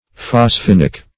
Search Result for " phosphinic" : The Collaborative International Dictionary of English v.0.48: Phosphinic \Phos*phin"ic\ (f[o^]s*f[i^]n"[i^]k), a. (Chem.)